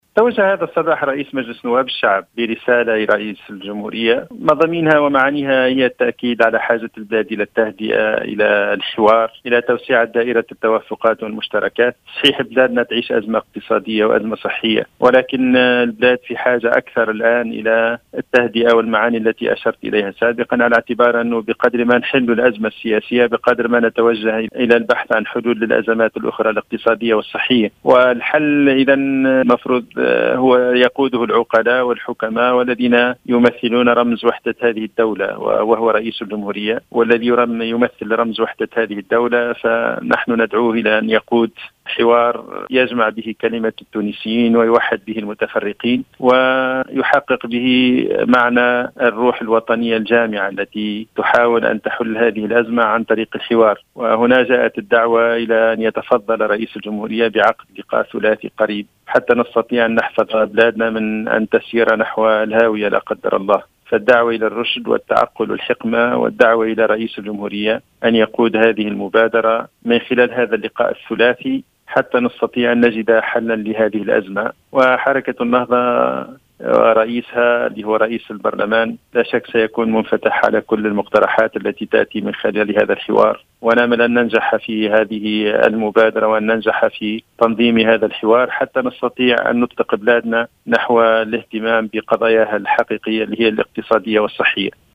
أرسل اليوم السبت 30 فيفري2021 ، رئيس مجلس نواب الشعب راشد الغنوشي مراسلة إلى رئيس الجمهورية قيس سعيد في إطار السعي لحل أزمة التحوير الوزاري ،وفق ما صرّح به للجوهرة أف أم الناطق الرسمي باسم حركة النهضة فتحي العيادي.